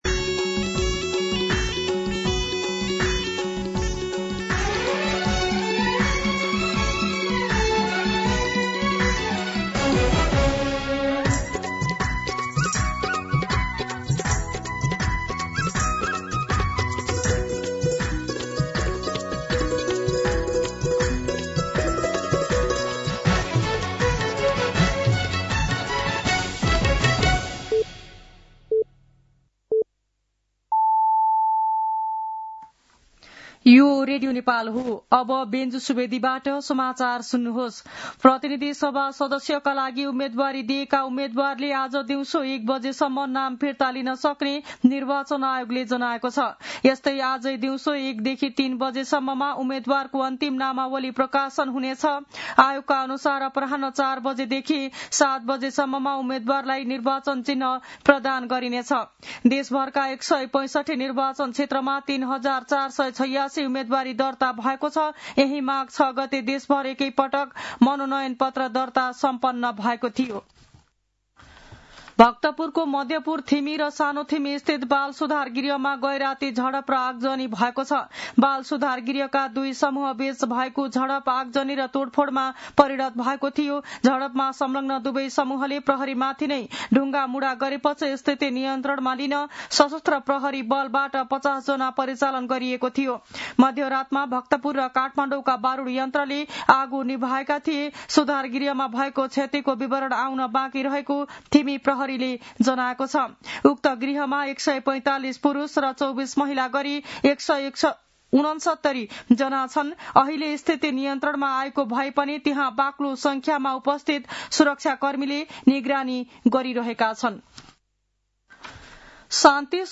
मध्यान्ह १२ बजेको नेपाली समाचार : ९ माघ , २०८२